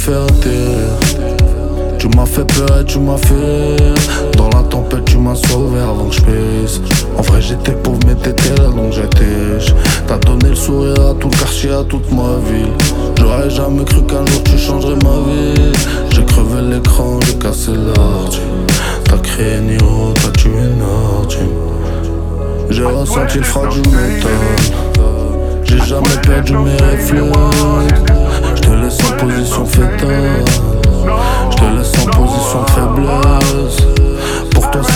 Rap Hip-Hop Rap
Жанр: Хип-Хоп / Рэп